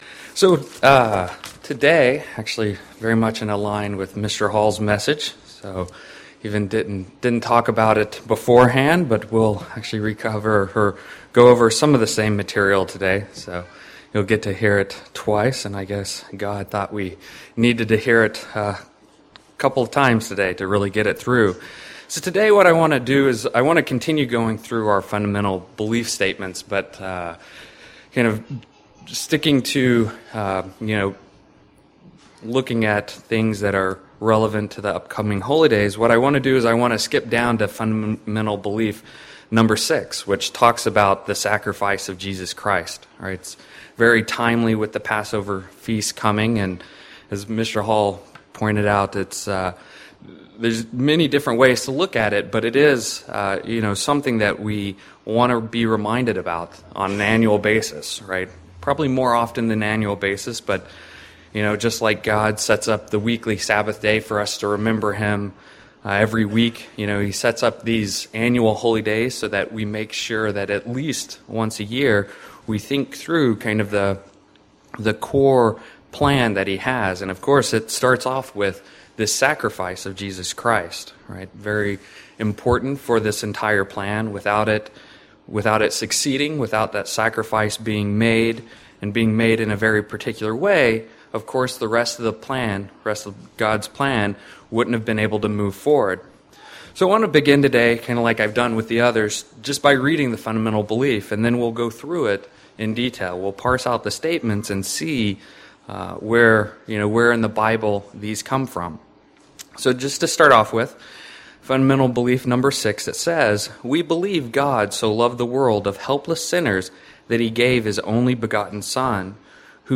The sermon explores Fundamental Belief #6 the Sacrifice of Jesus Christ and what it can tell us during the Passover season.